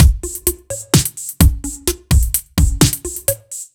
Index of /musicradar/french-house-chillout-samples/128bpm/Beats